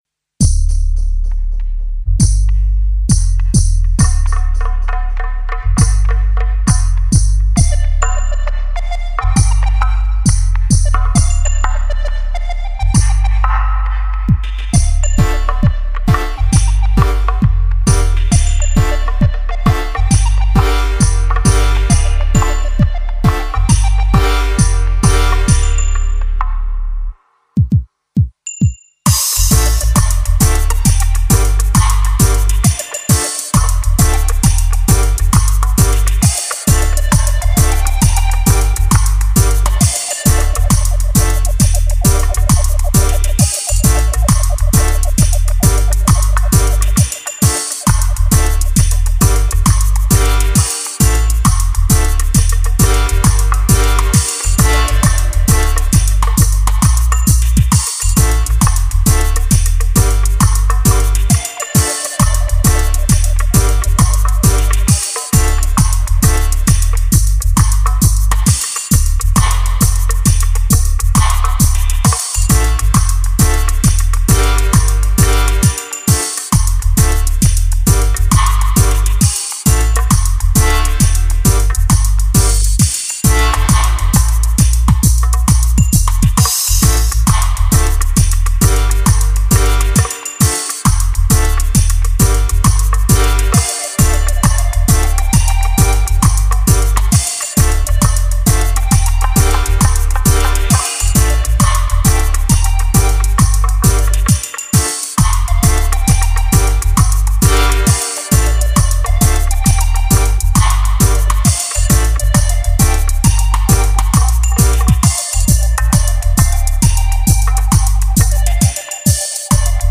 instrumental sample